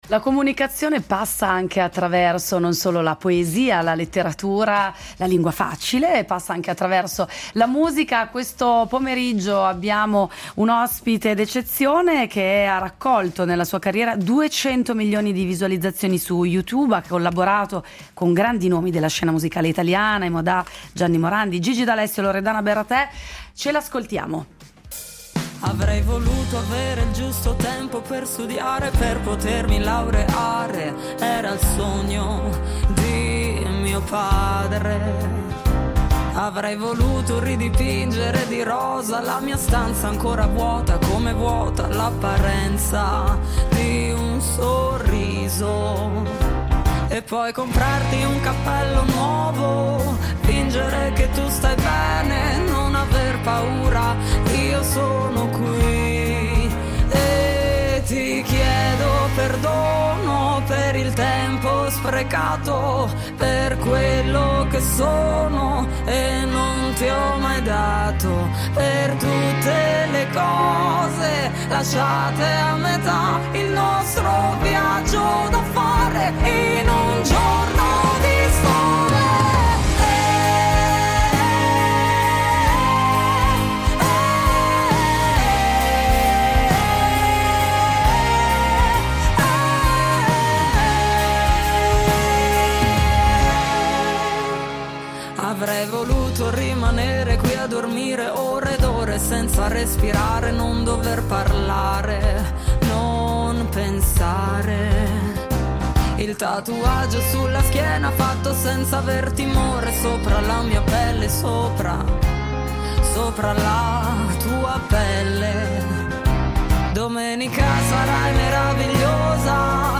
Incontro con Bianca Atzei, cantautrice italiana